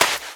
High Quality Footsteps
STEPS Sand, Run 11.wav